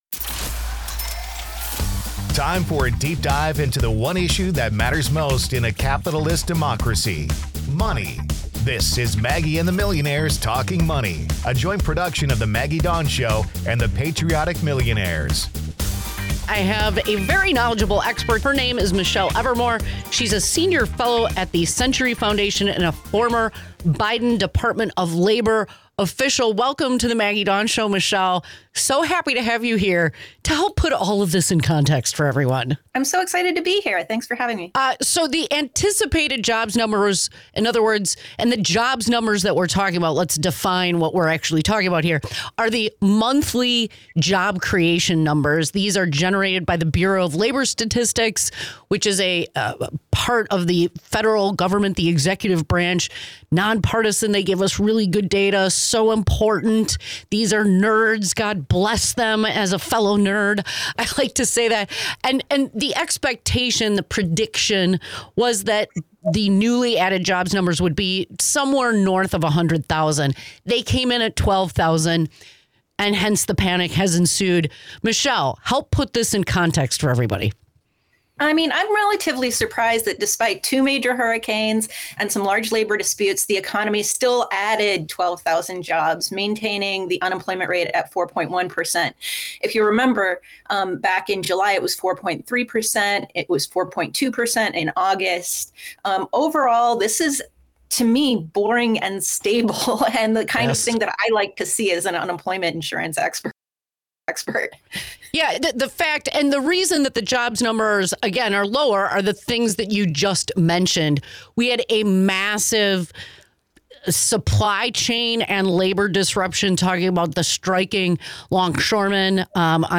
This exciting interview talks about tax in the community and a woman's value in society is society should be equal to men.